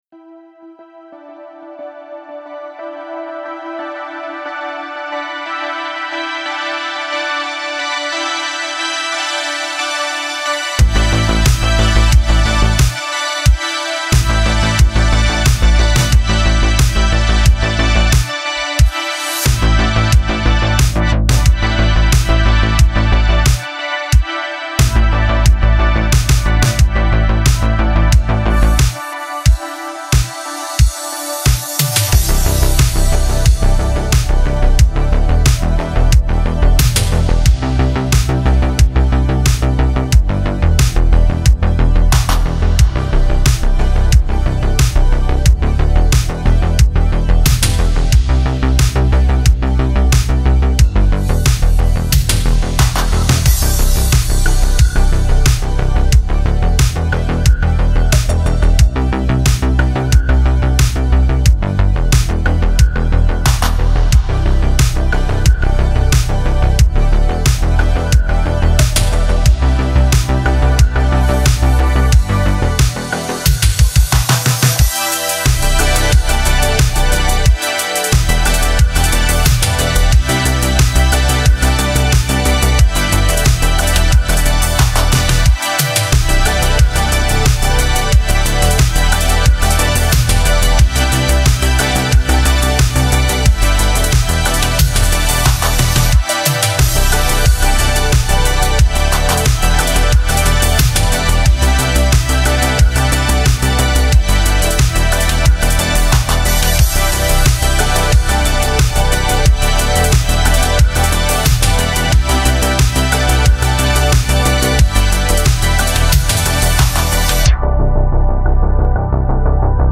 Genre: Synthwave, Retrosynth, Darkwave, Outrun, Electronic.